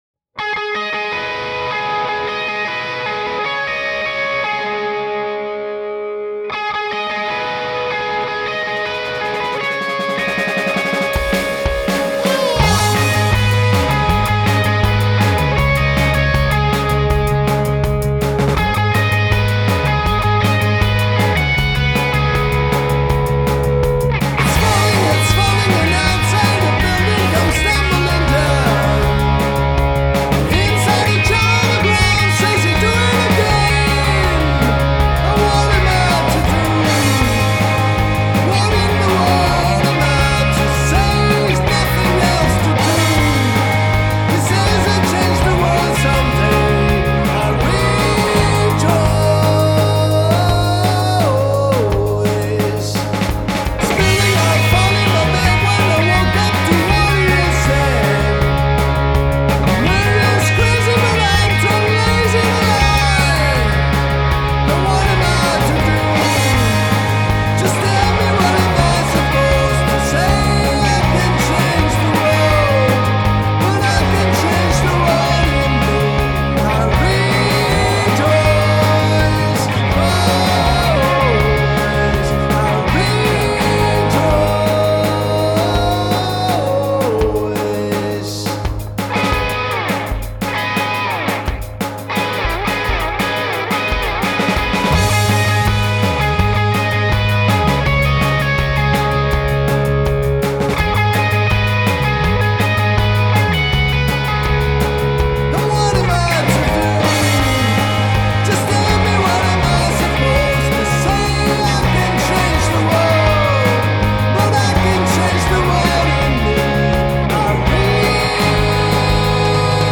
Live Version (Red Rocks)
Les Paul Standard 2012 on BRIDGE Pickup (BurstBucker Pro).